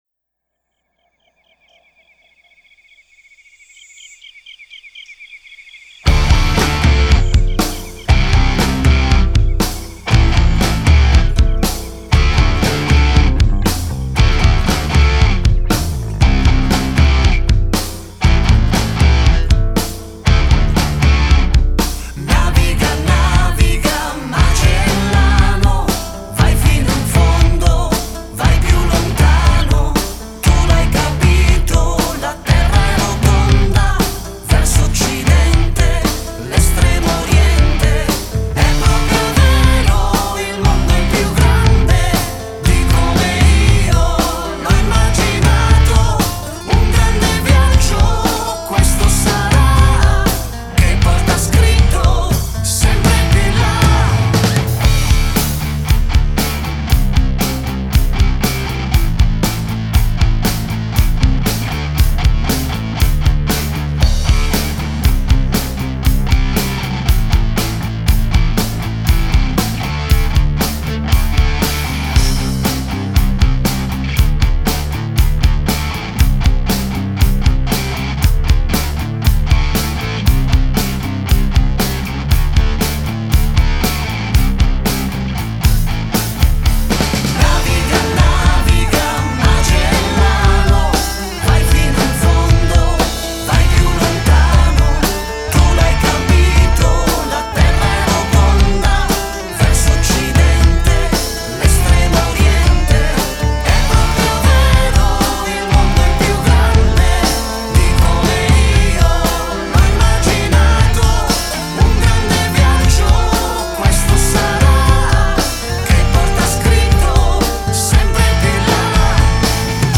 Ascolta la base musicale